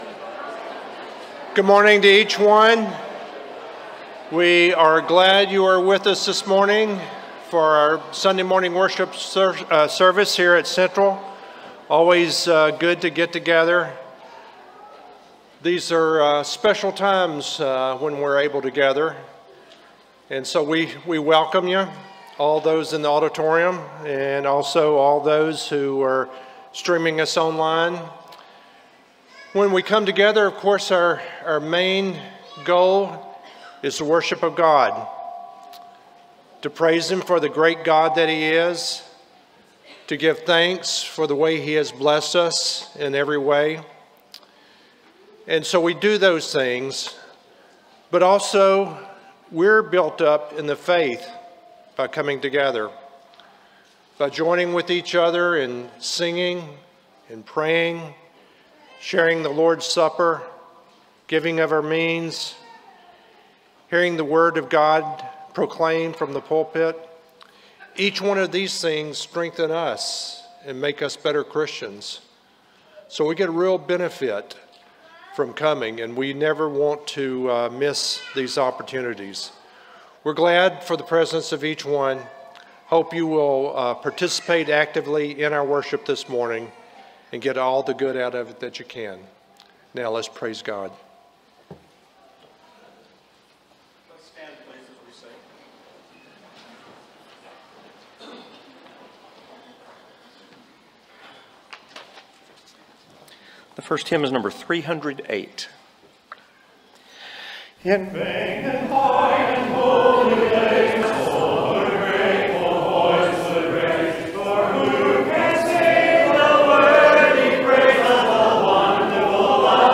Psalm 51:12 (English Standard Version) Series: Sunday AM Service